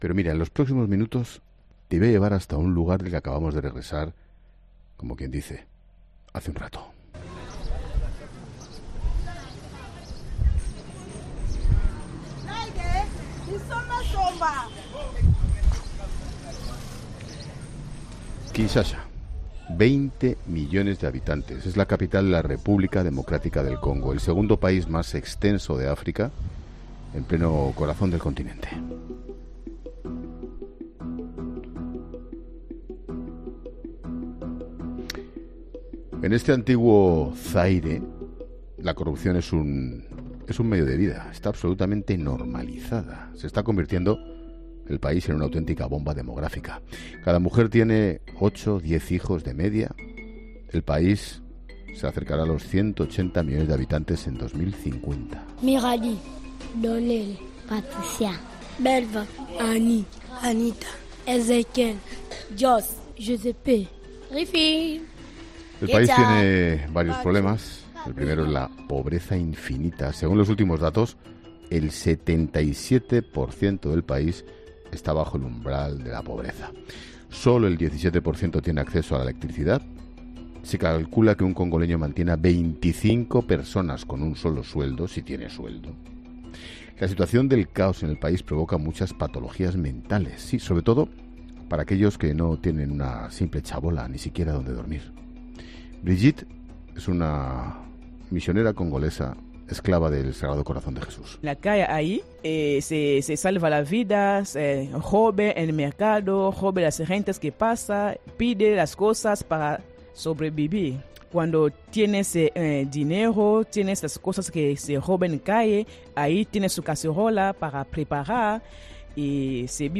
Un equipo de La Linterna ha viajado hasta Kinshasa en la previa de la llegada de Francisco.